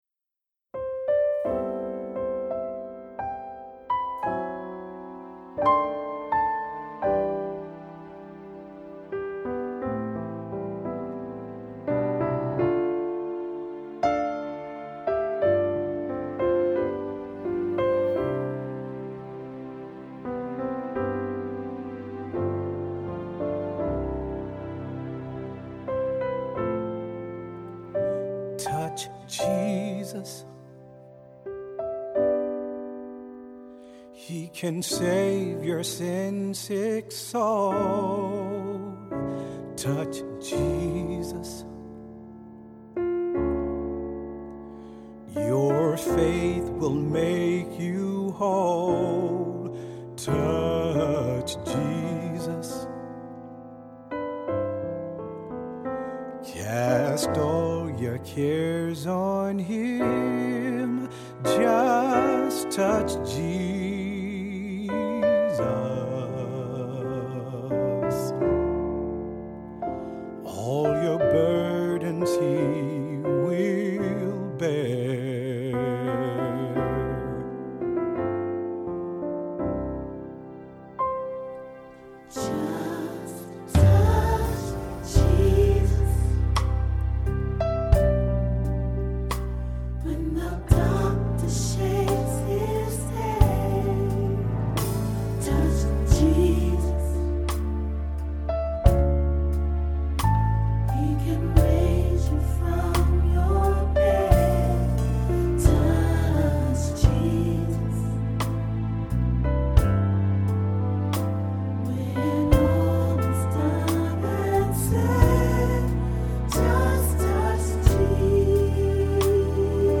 Voicing: SAT; Three-part equal; Solo; Assembly